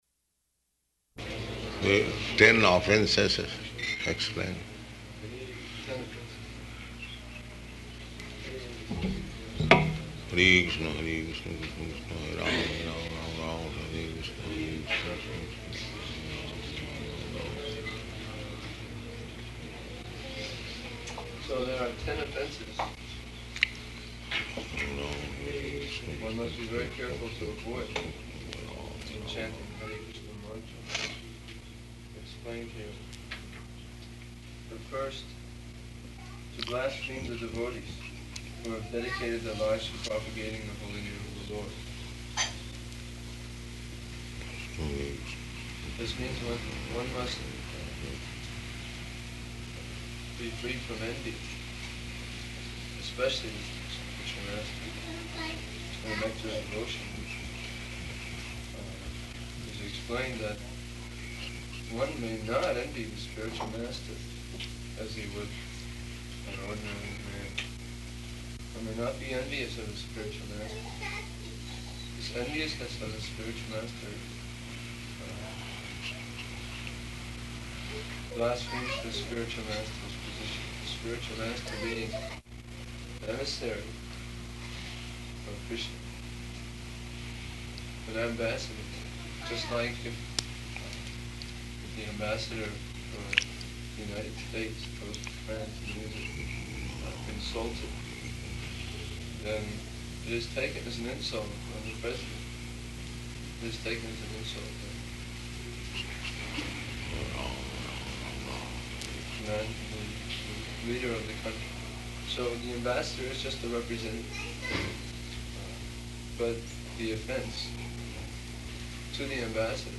Initiations --:-- --:-- Type: Initiation Dated: July 18th 1971 Location: Detroit Audio file: 710718IN-DETROIT.mp3 Prabhupāda: The ten offenses, explain.